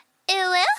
Youtube Uwuuuuu Sound Effect Free Download